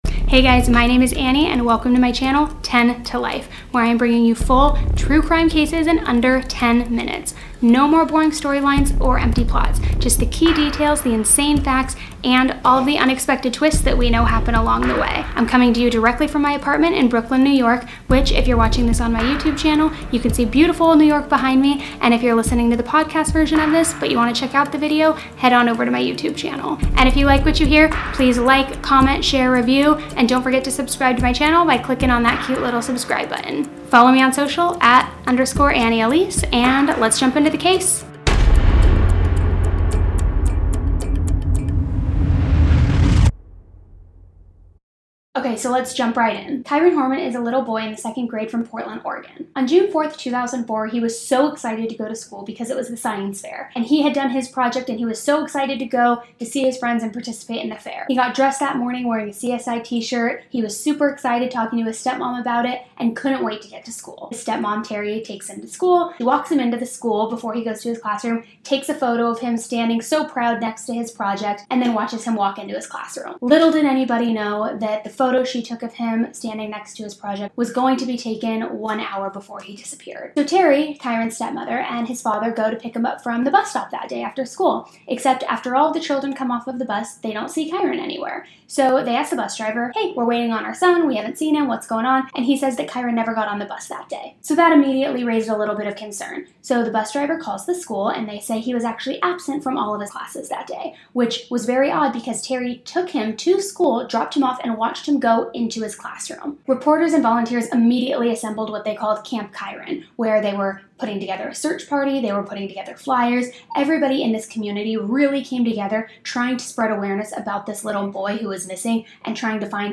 Even better, you’ll hear the story the way you’d want to: like your best friend is filling you in.